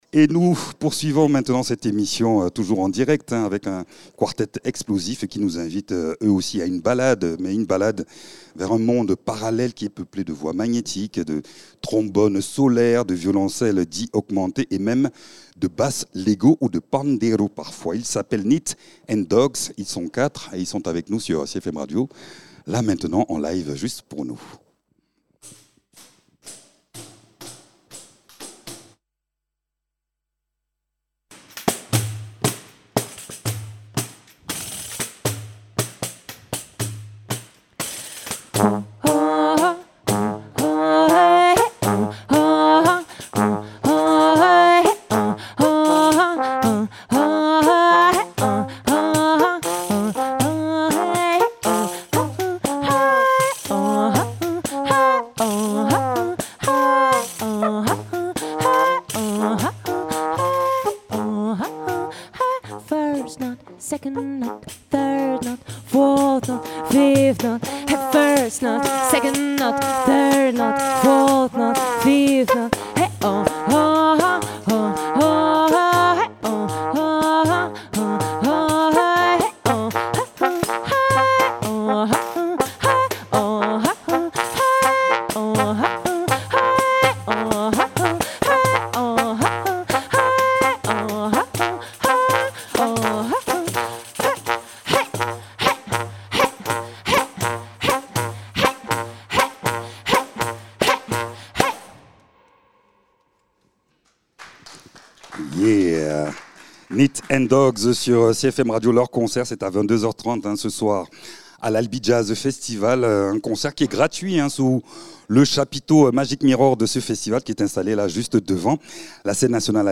Albi Jazz Festival